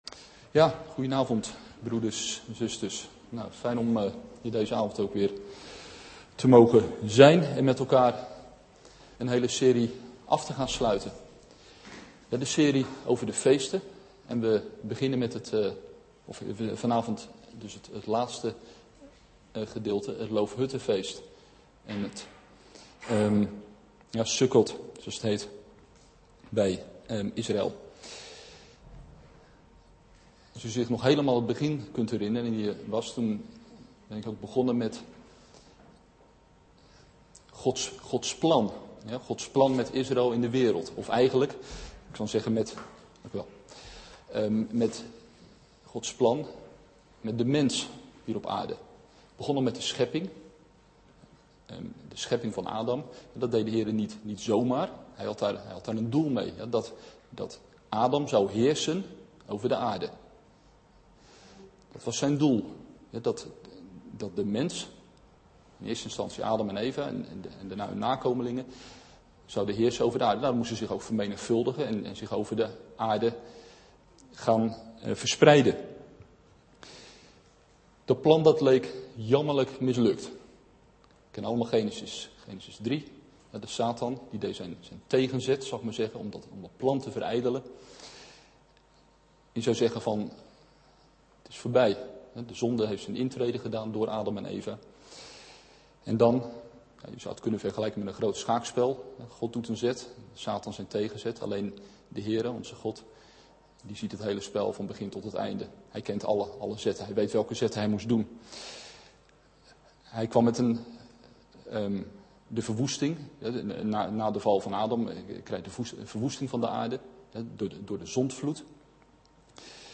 AfspelenDeze preek is onderdeel van de serie:"De feesten des HEEREN"DownloadAudiobestand (MP3) Powerpoint